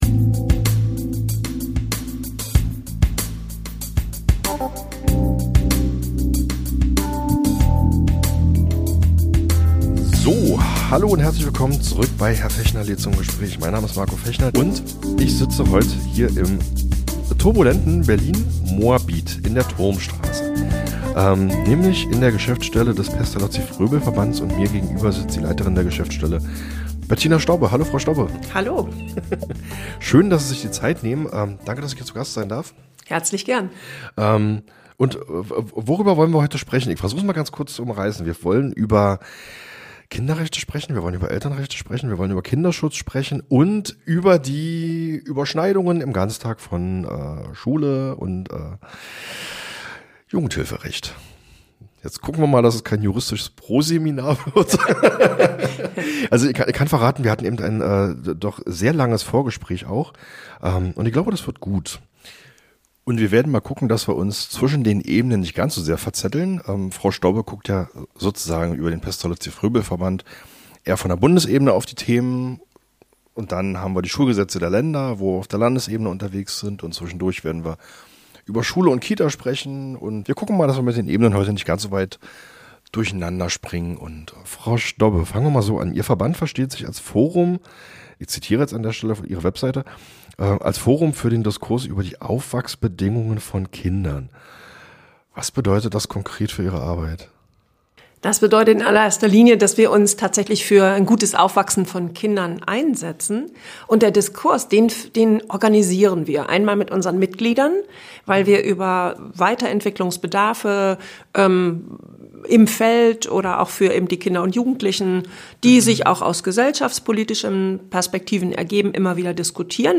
Der Bildungspodcast Podcast